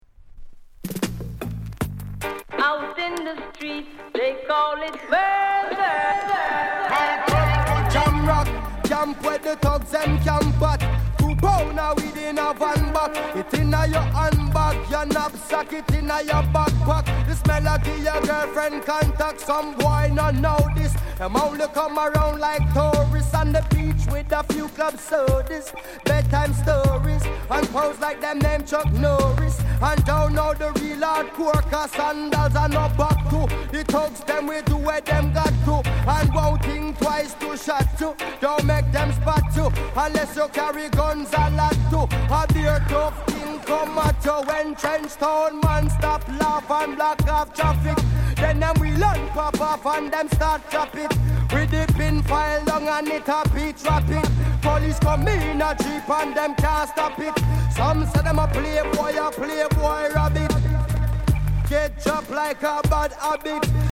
SOUND CONDITION VG